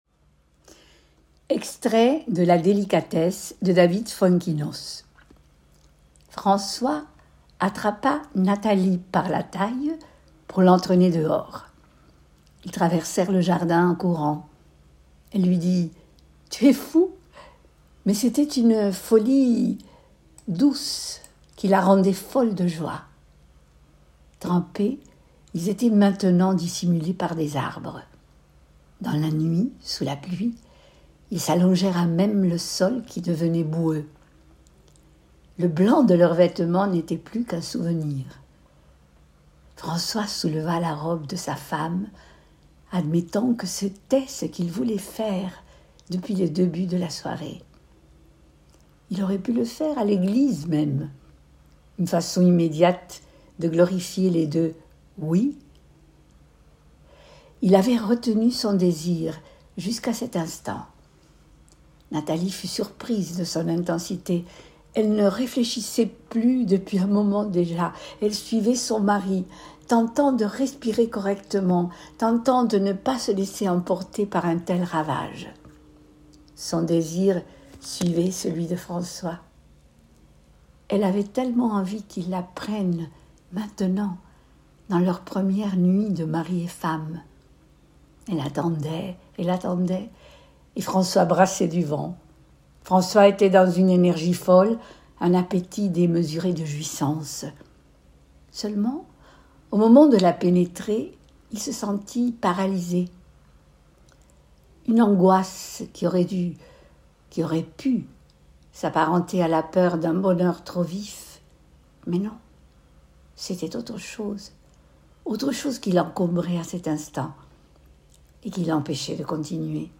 Extrait VOIX 2 pour livre audio